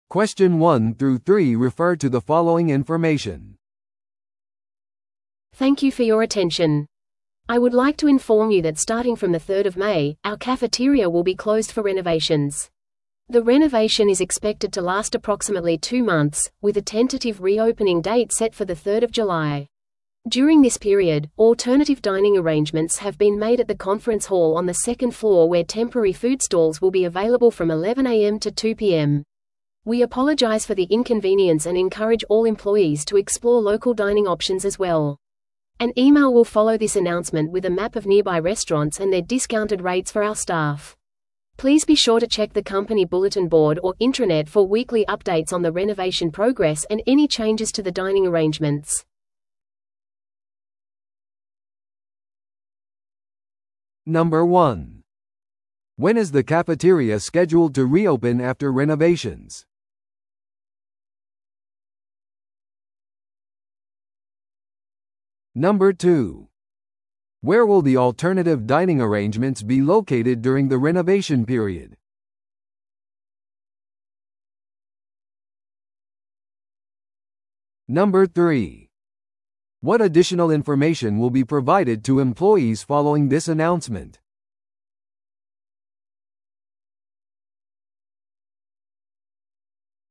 TOEICⓇ対策 Part 4｜食堂の改修閉鎖告知 – 音声付き No.074